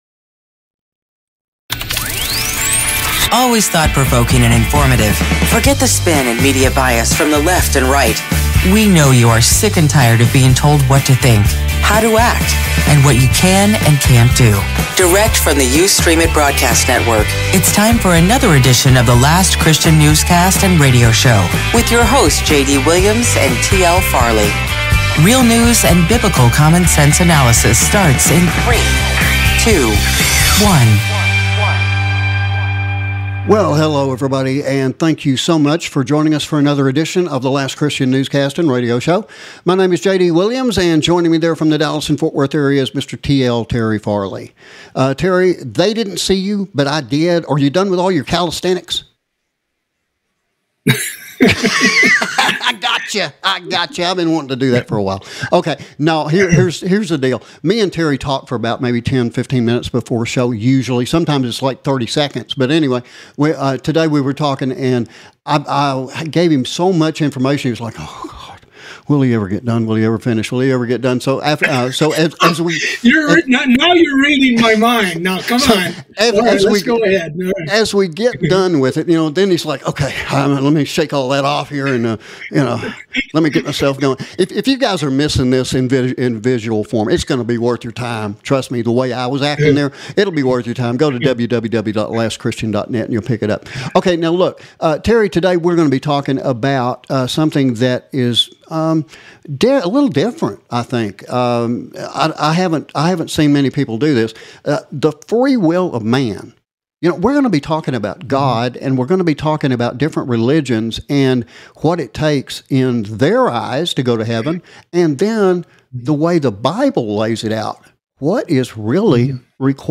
In this edition of the Newscast we examine the ongoing internal conflict and debate within the Nation of Israel. Is it just possible that events having occurred in Genesis Chapter 2 are the initial cause of all problems seen in the Middle East today?